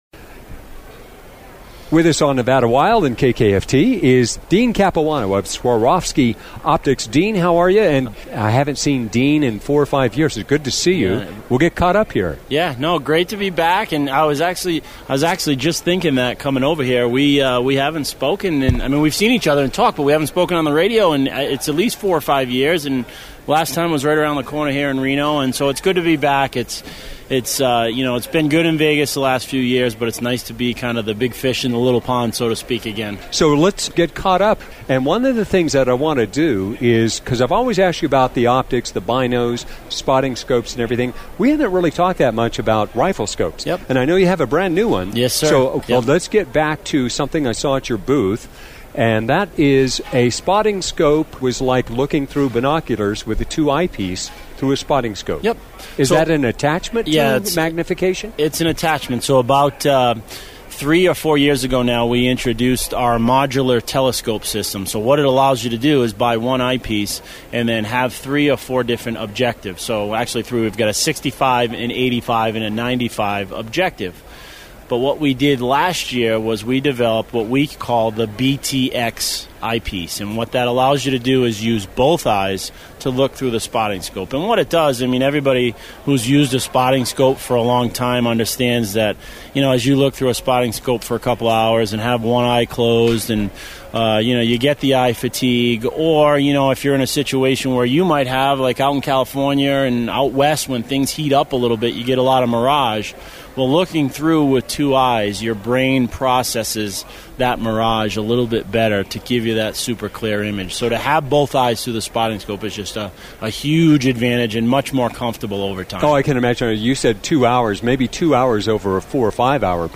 Recorded at SCI Jan 2019.